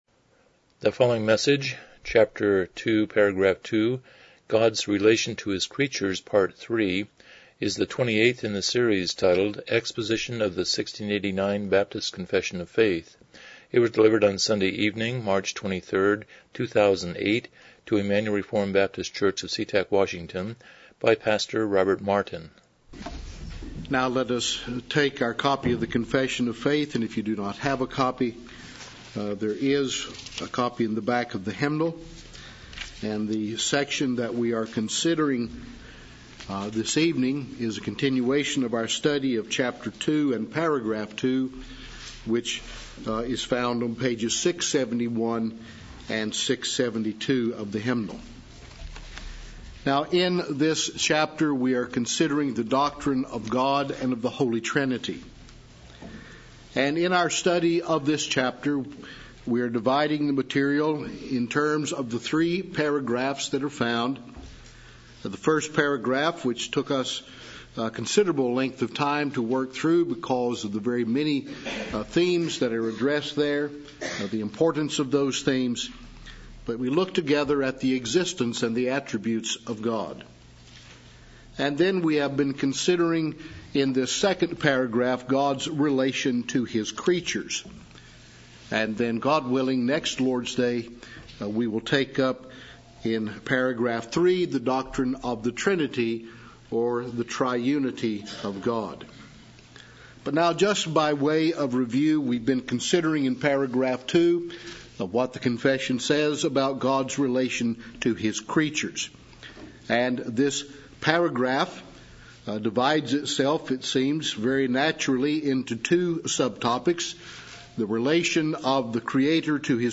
Series: 1689 Confession of Faith Service Type: Evening Worship